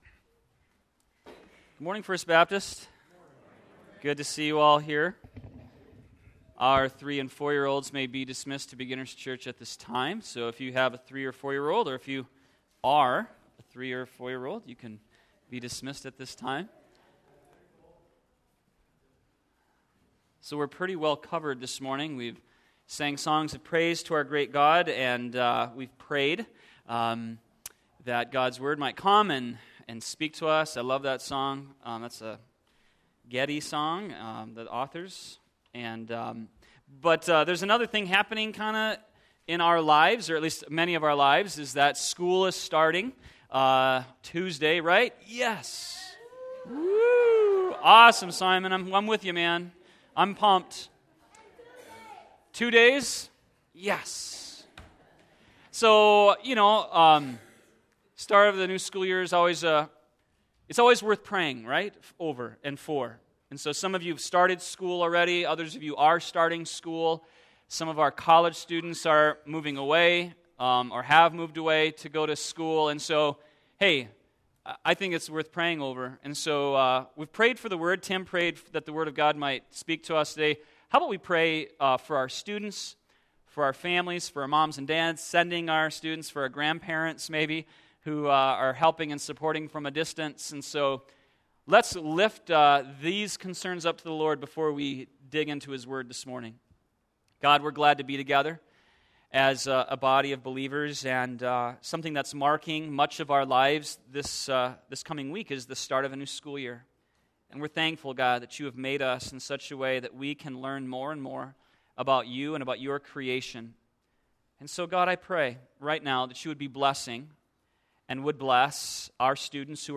sermon9113.mp3